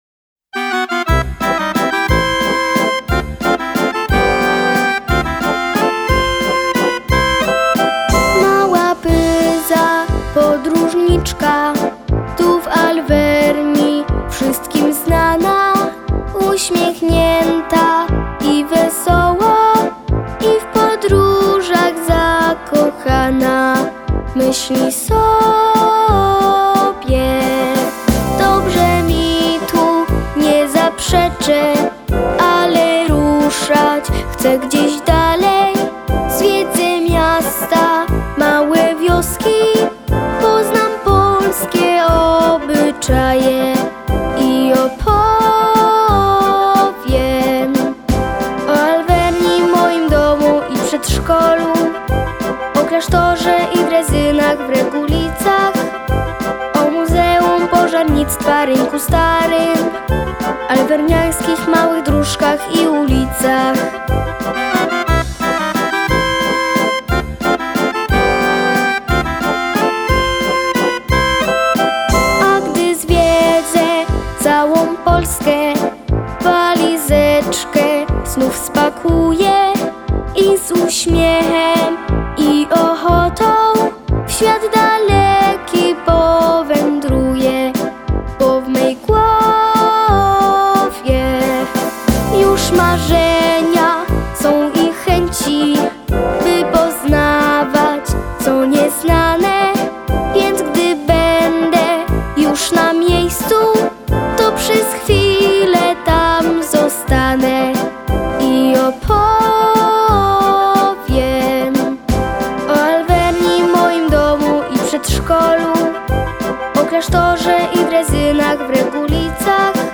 Jeśli jeszcze nie znasz , naucz się piosenki o Pyzie i o Alwerni – chętnie posłuchamy jak śpiewasz!!!!